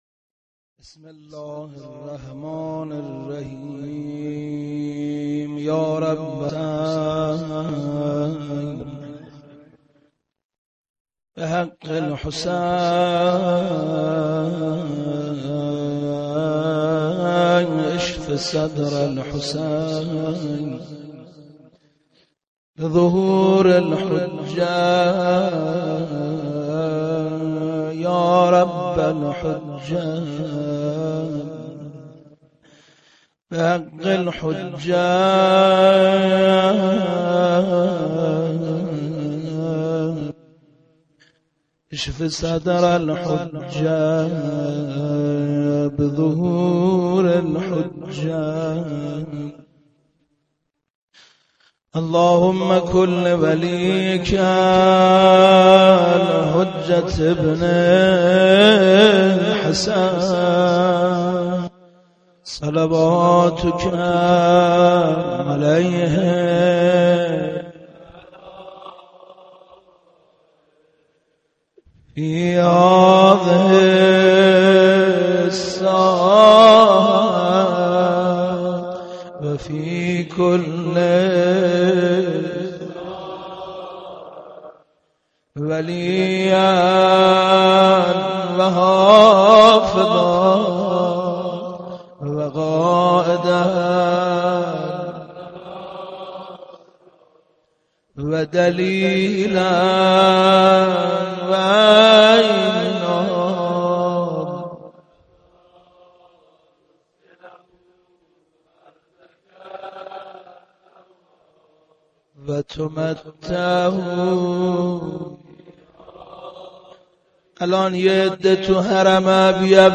صوت زیارت اربعین